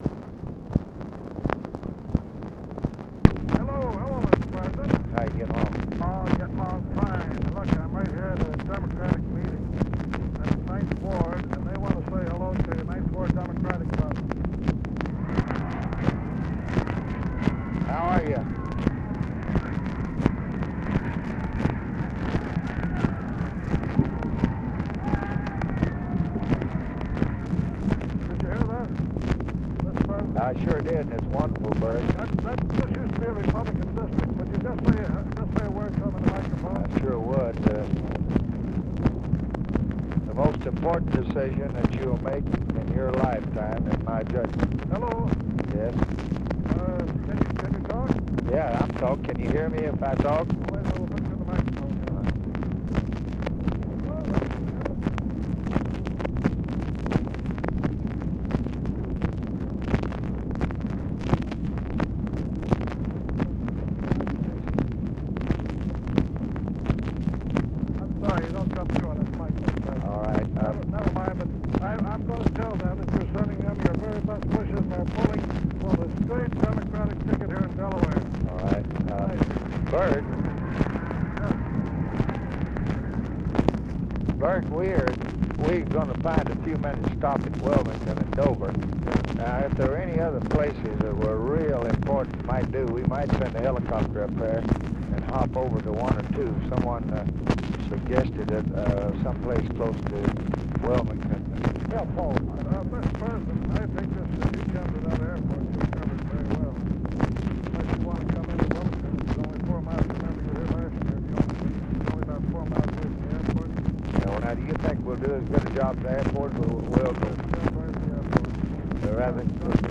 Conversation with ELBERT CARVEL, October 30, 1964
Secret White House Tapes